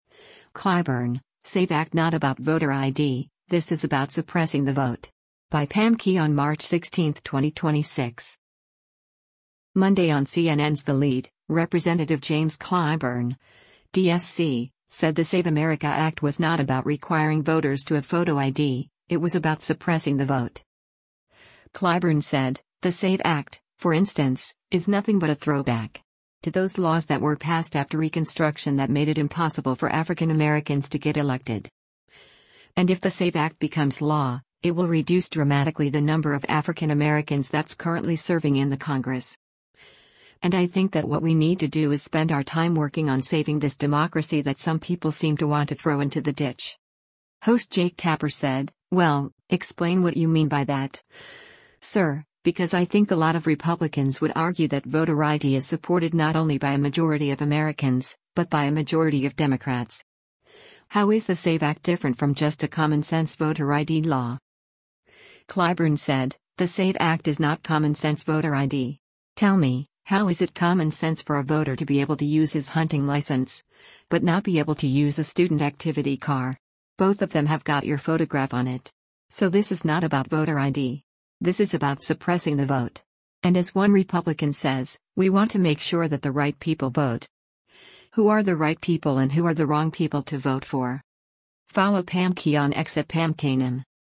Monday on CNN’s “The Lead,” Rep. James Clyburn (D-SC) said the SAVE America Act was not about requiring voters to have photo ID, it was “about suppressing the vote.”